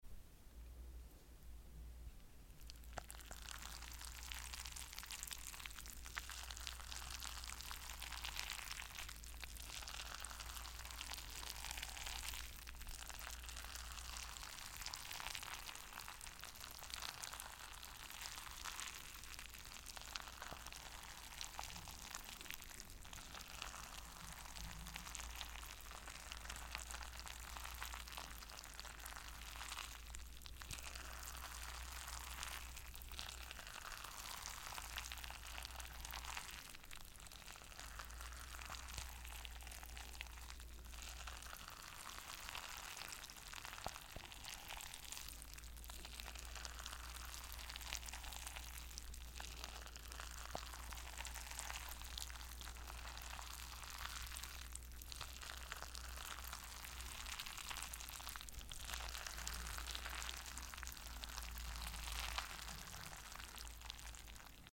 Broken marbles